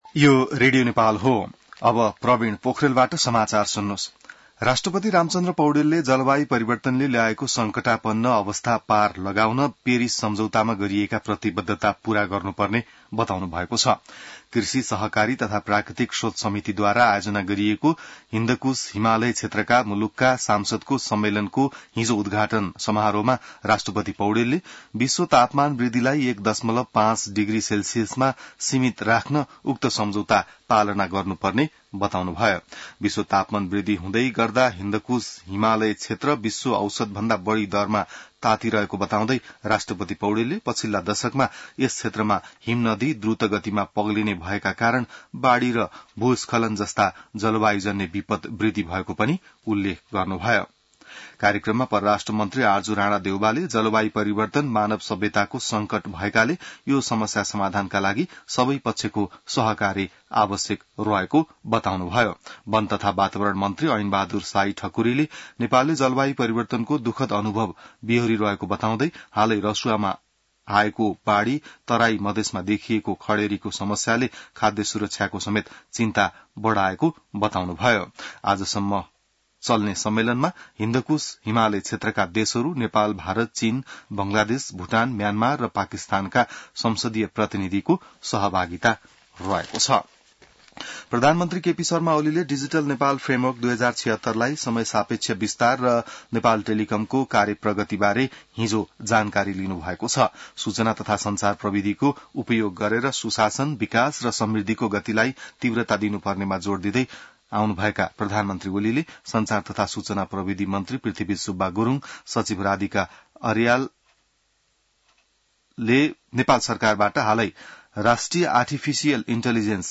बिहान ६ बजेको नेपाली समाचार : ३ भदौ , २०८२